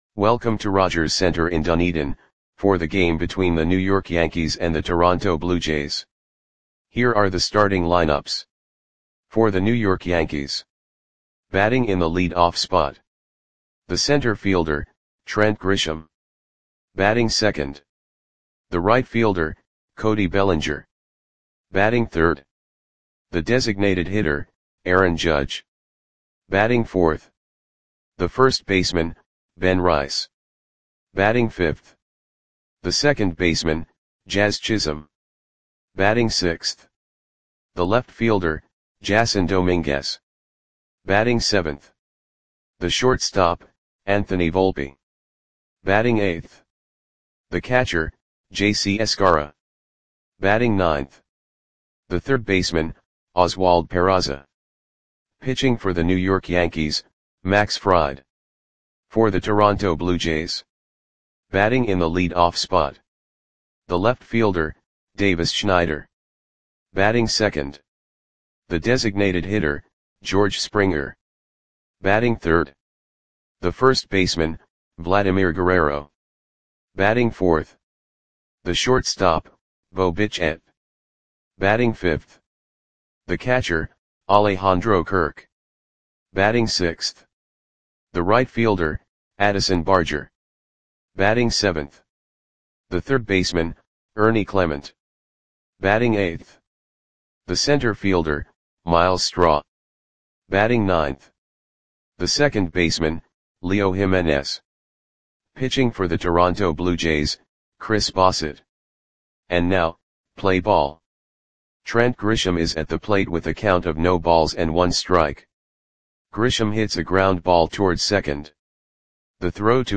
Audio Play-by-Play for Toronto Blue Jays on July 23, 2025
Click the button below to listen to the audio play-by-play.